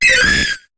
Cri de Corayon dans Pokémon HOME.